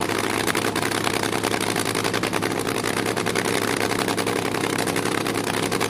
Boat Race Loop Single Boat, Fast Idle